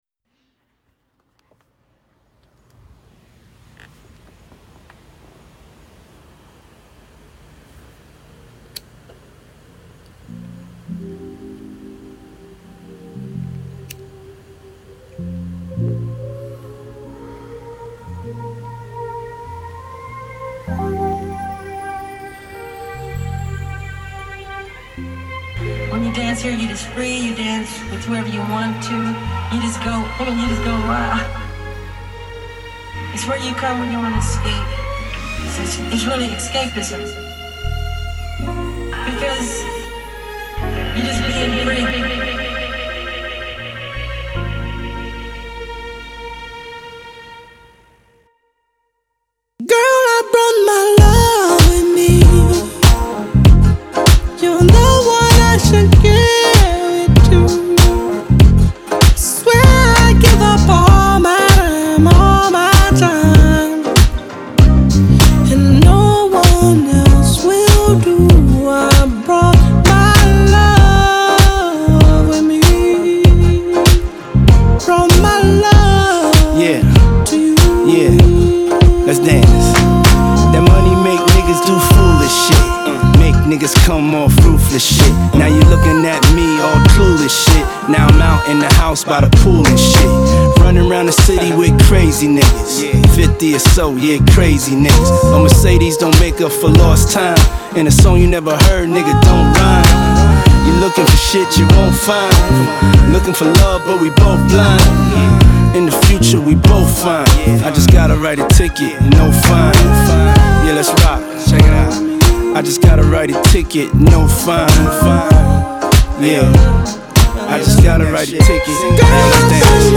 Genre : Soul, Funk, R&B